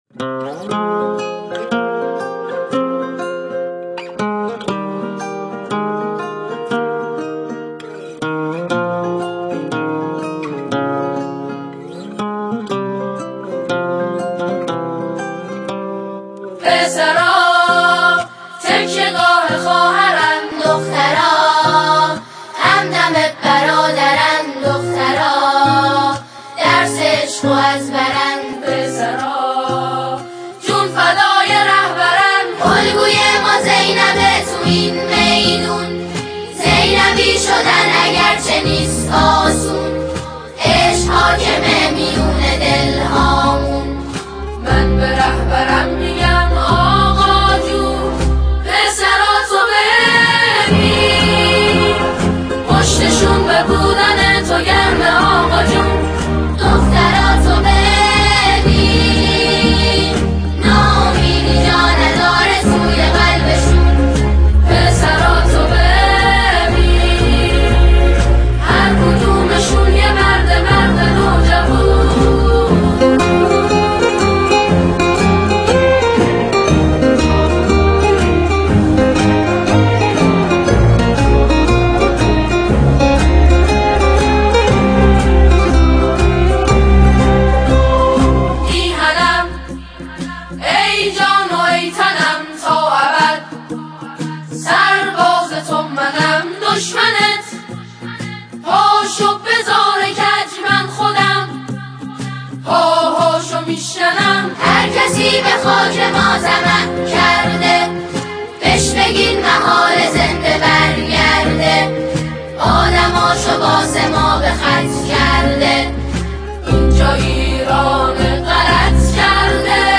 ژانر: سرود ، سرود انقلابی